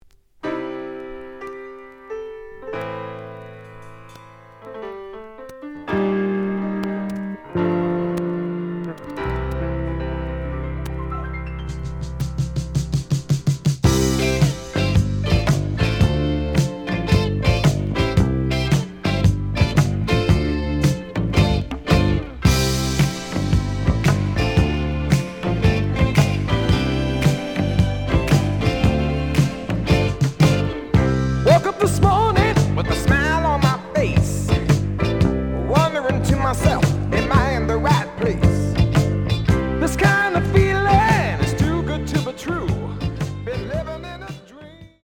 The audio sample is recorded from the actual item.
●Genre: Disco
Some noise on beginning of A side, but almost good.)